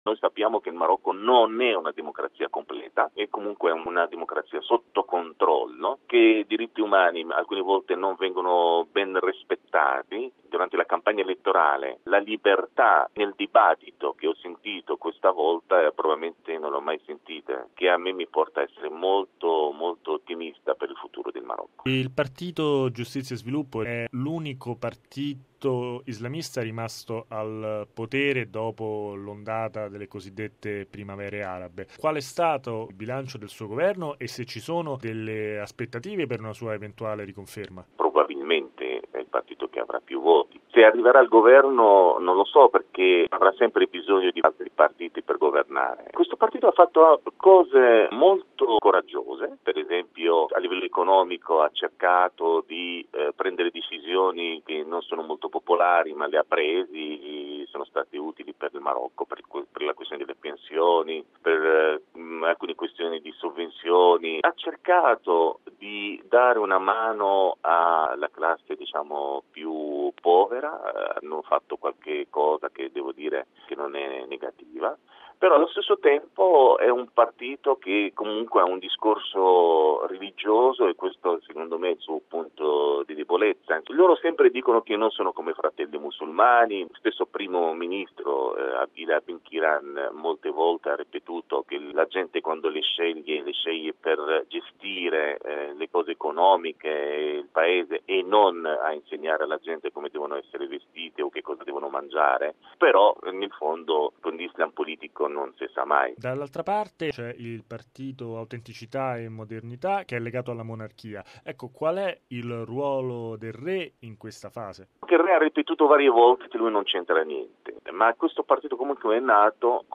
il giornalista marocchino